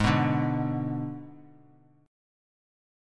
Abdim chord